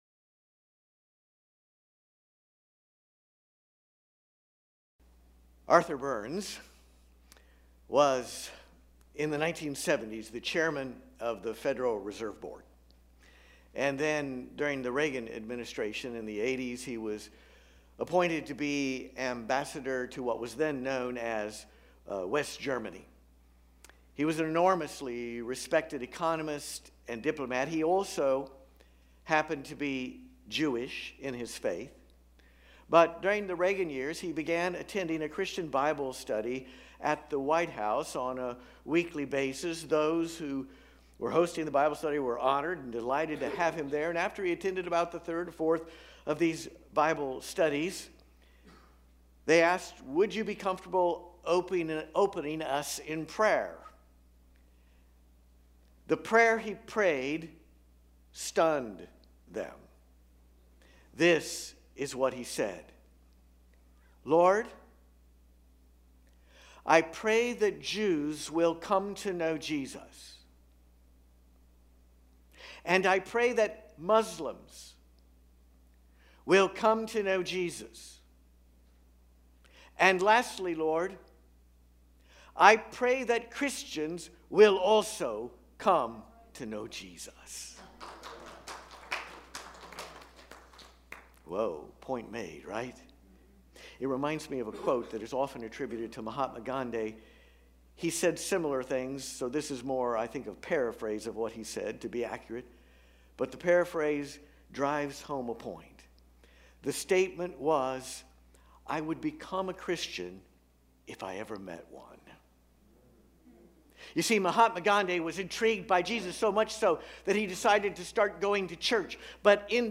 Join us on Sunday for a thought-provoking sermon